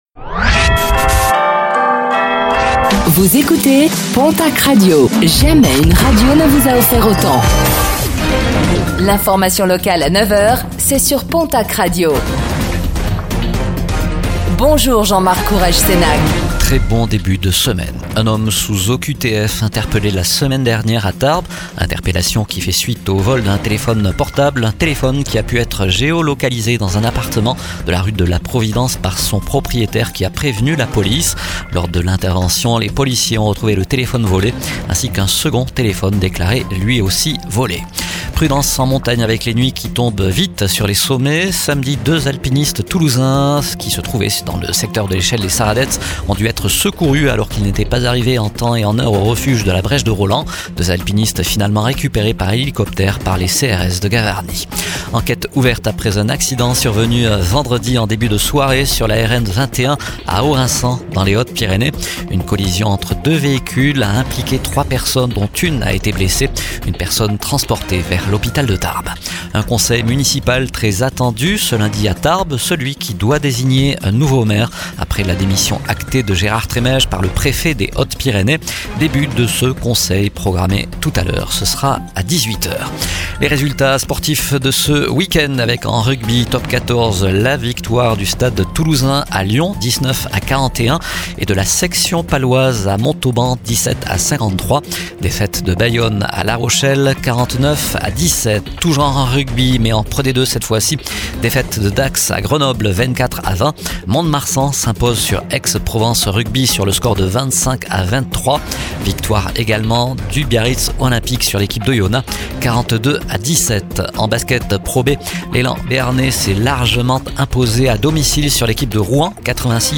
09:05 Écouter le podcast Télécharger le podcast Réécoutez le flash d'information locale de ce lundi 22 décembre 2025